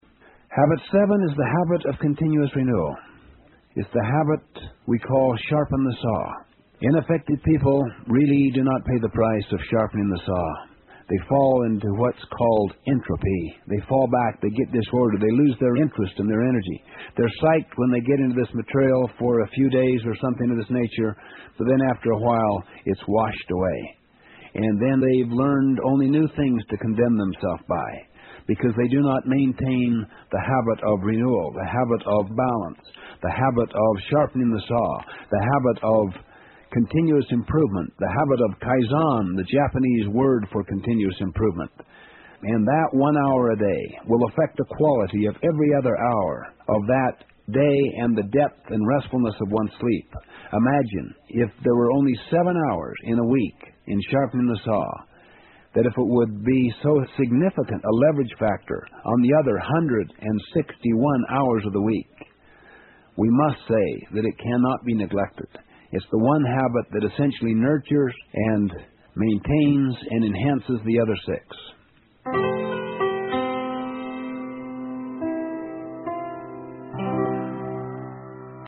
有声畅销书：与成功有约08 听力文件下载—在线英语听力室